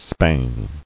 [spang]